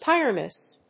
音節Pyr・a・mus 発音記号・読み方
/pírəməs(米国英語)/